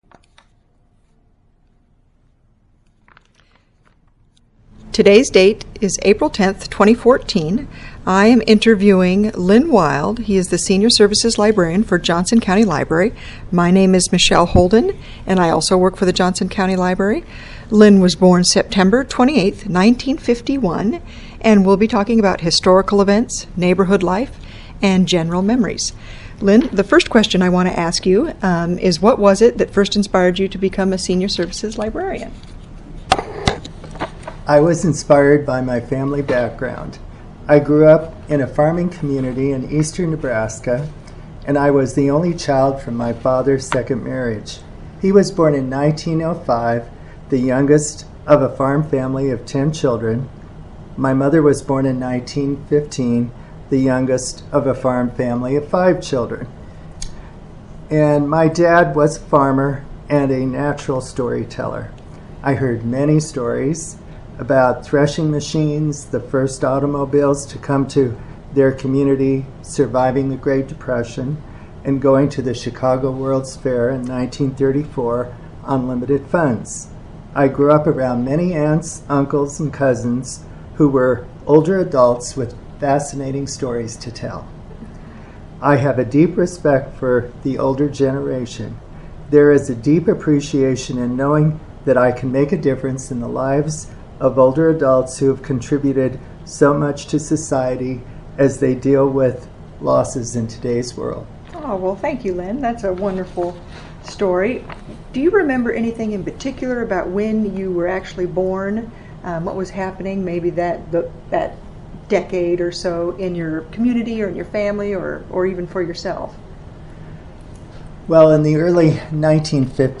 Interviewer Relation: Co-worker
interviewer
interviewee